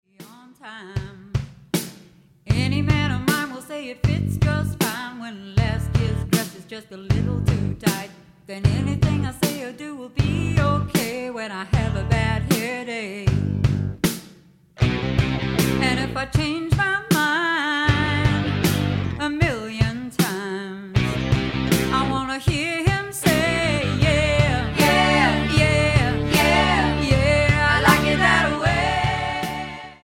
Genre / Stil: Country & Folk